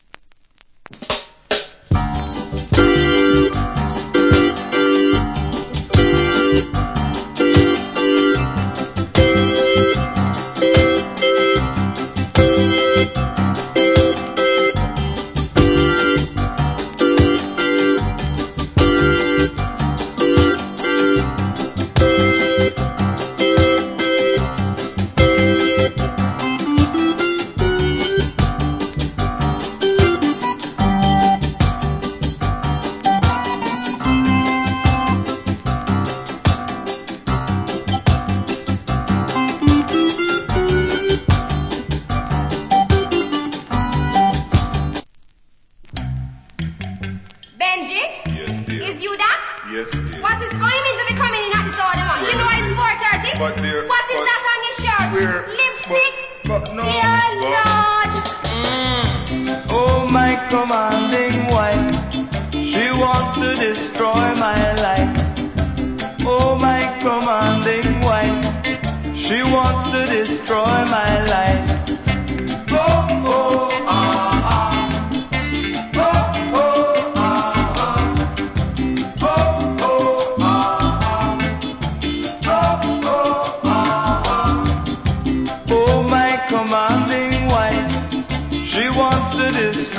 Wicked Organ Early Reggae Inst.